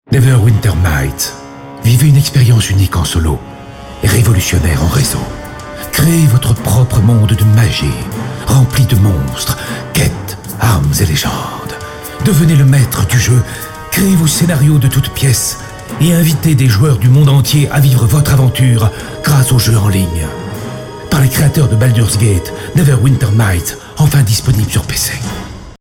Publicité jeu vidéo